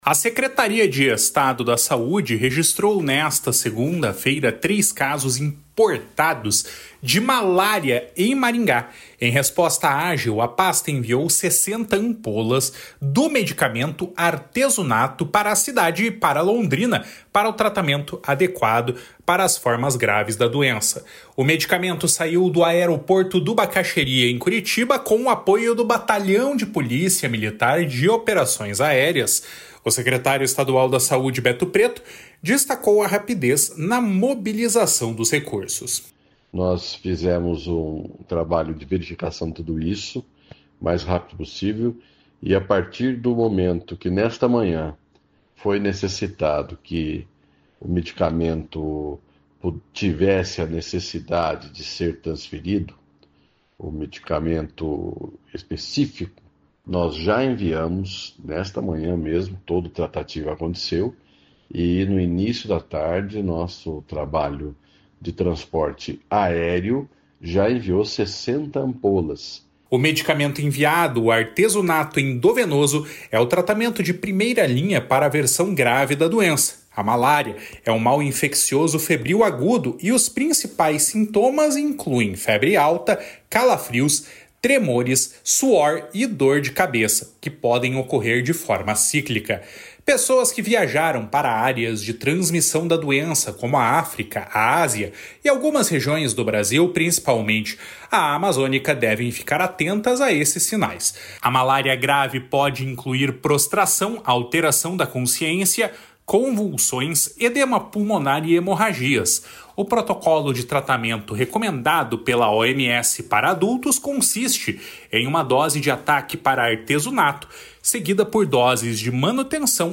O secretário estadual da Saúde, Beto Preto, destacou a rapidez na mobilização dos recursos. // SONORA BETO PRETO //